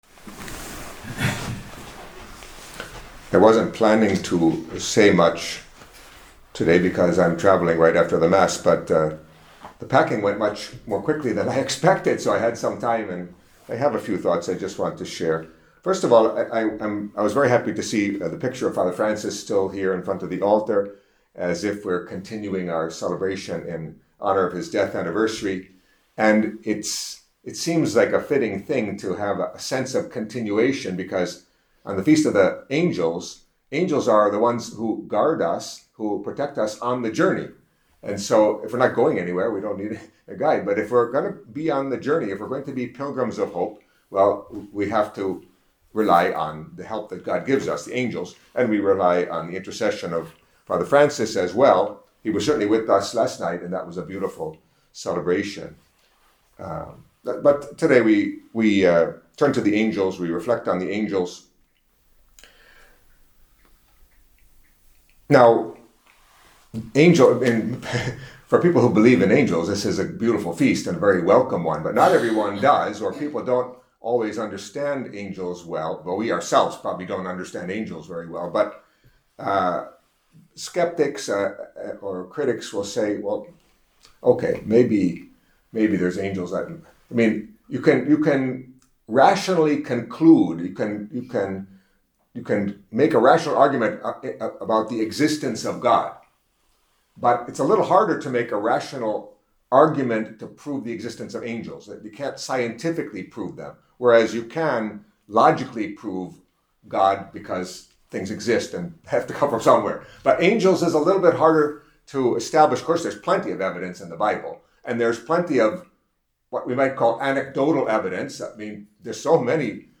Catholic Mass homily for Thursday of the Twenty-Sixth Week in Ordinary Time